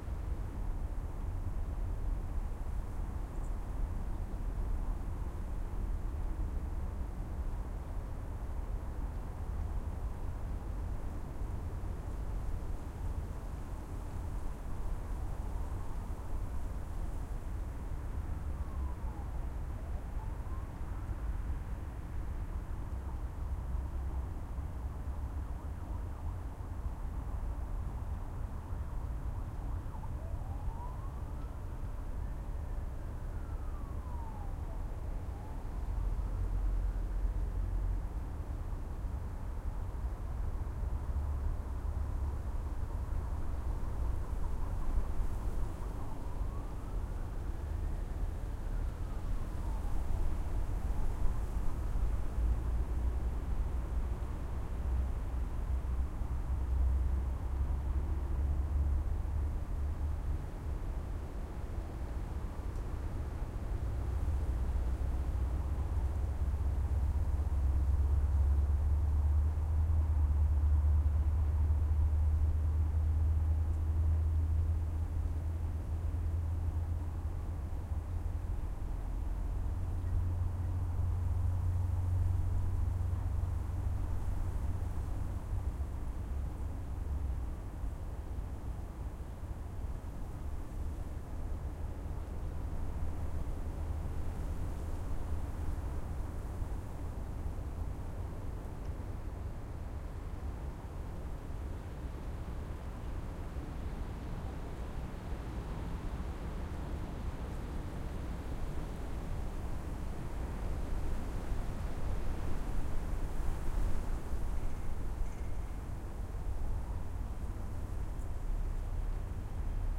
windLightLoop.ogg